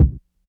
KICK IIII.wav